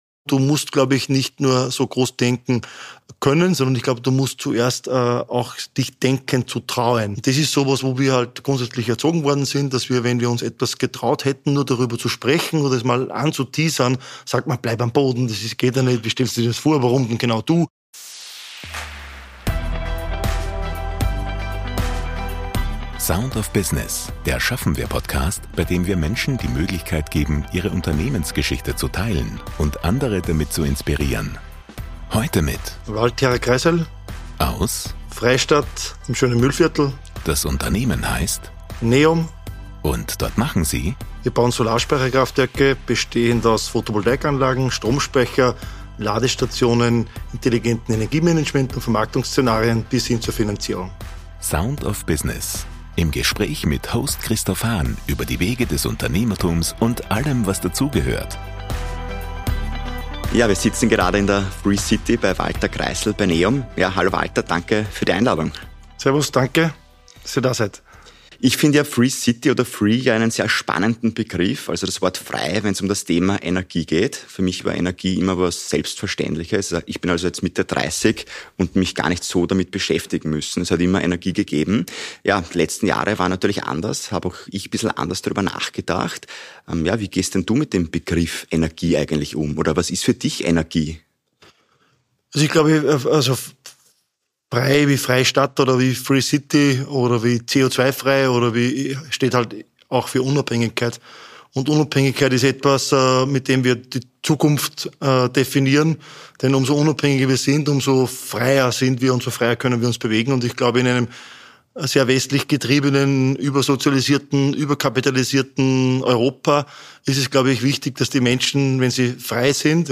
Beschreibung vor 1 Jahr Heute im Gespräch